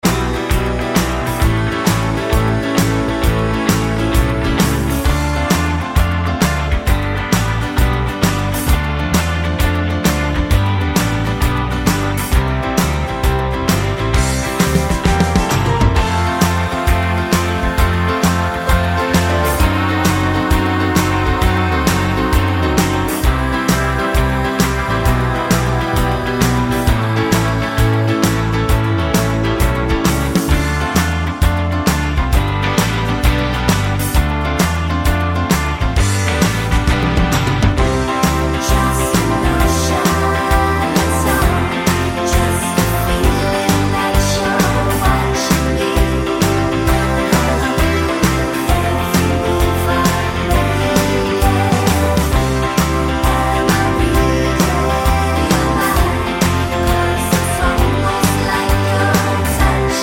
No Two Part Harmony Pop (1970s) 3:26 Buy £1.50